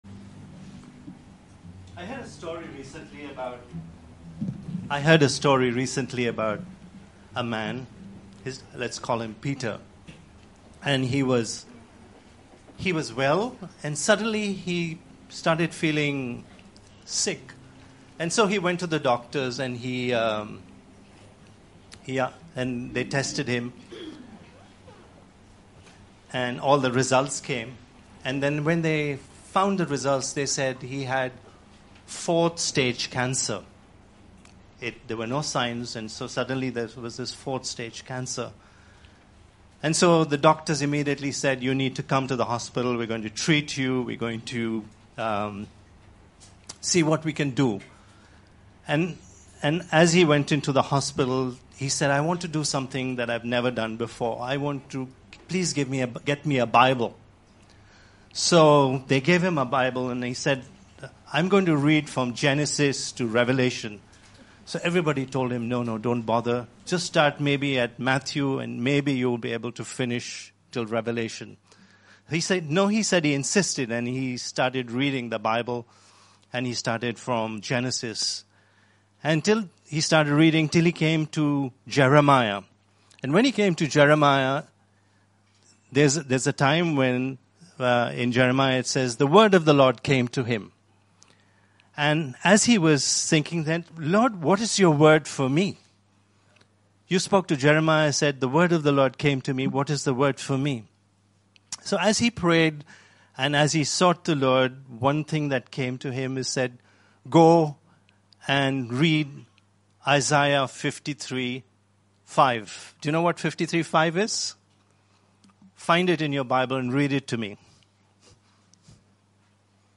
Sermon by